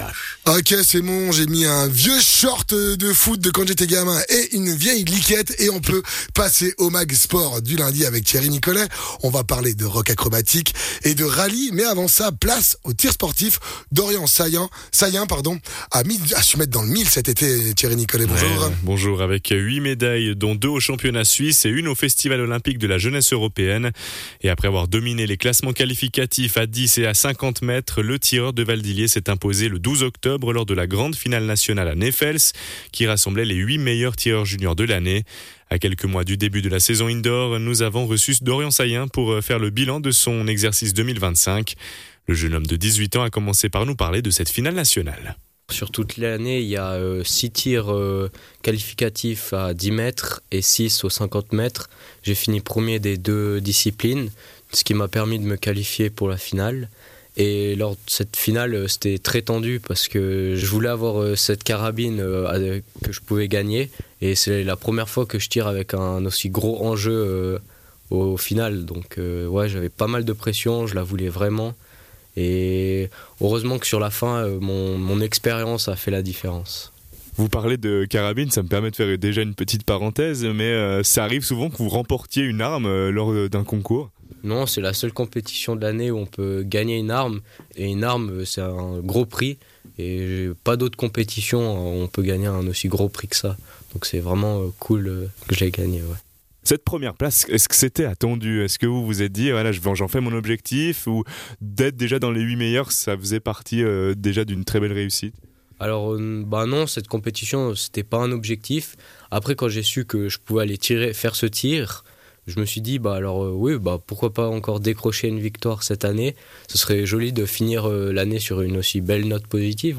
tireur sportif